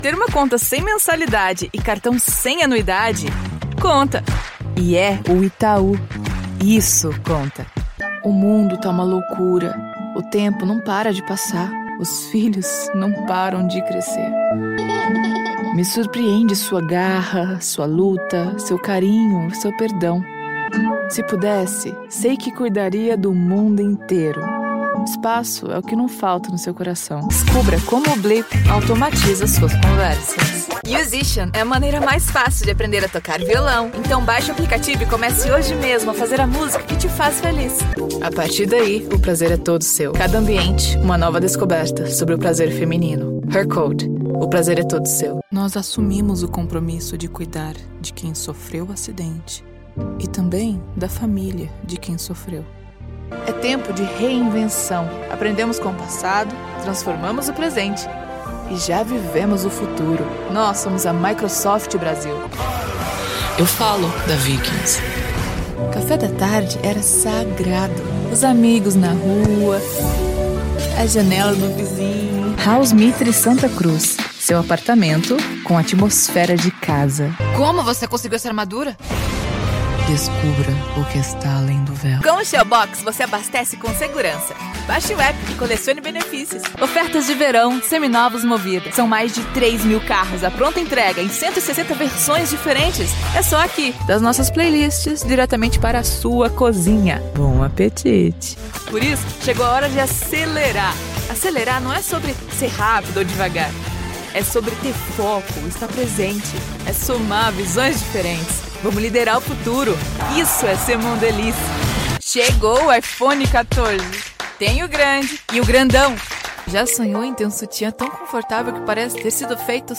Young, adult and middle-aged female voices.
Sprechprobe: Werbung (Muttersprache):
Native Brazilian Portuguese, International English or with a Brazilian Accent. Neutral accent from São Paulo or Carioca accent from Rio de Janeiro.
Natural, Grounded, Naked, Raw, Conversational, Real-person – but most importantly: unlimited and easy to direct. ▪ Clear, Distinct, Articulate, Modern contralto voice with Edge; ▪ Unpolished, Relatable, Casual, Lively, Young voice for Millennials & Gen Z; ▪ Reassuring, Warm, Empathetic, Genuine & Helpful for all ages, especially children.